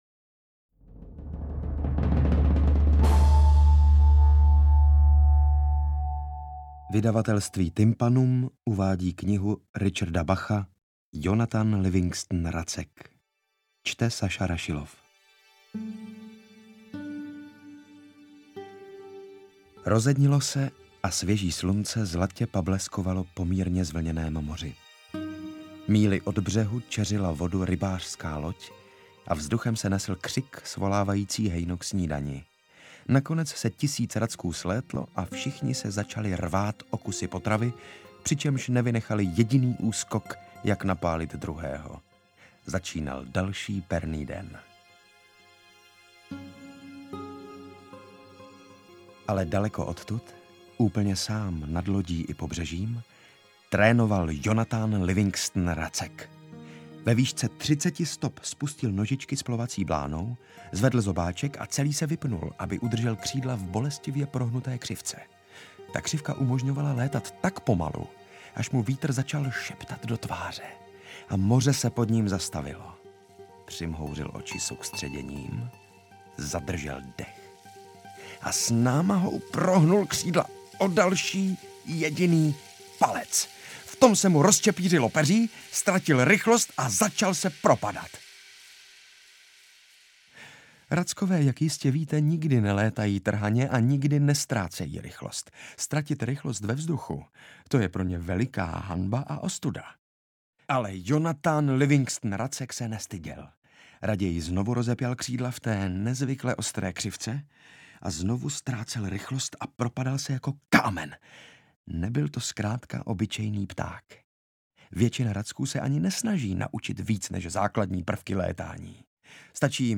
Interpret:  Saša Rašilov
Audioknižní podoba novely Richarda Bacha kterou interpretuje Saša Rašilov.